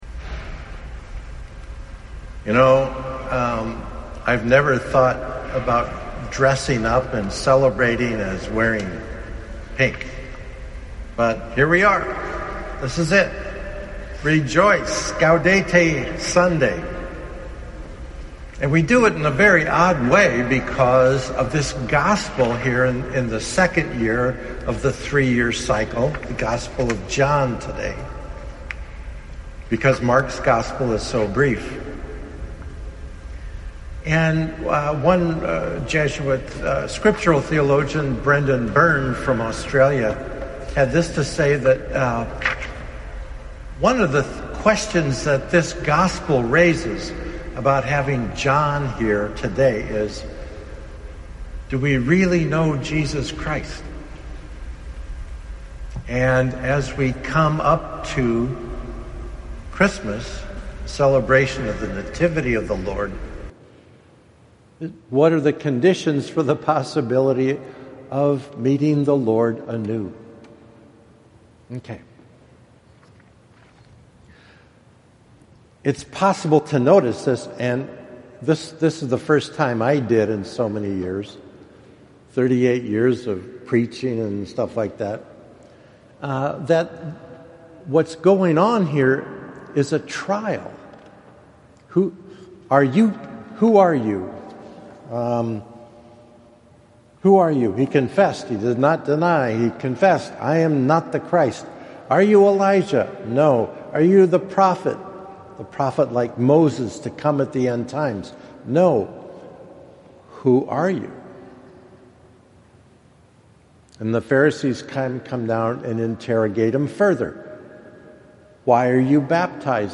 And here’s the audio of my first homily for the 3rd Sunday of Advent.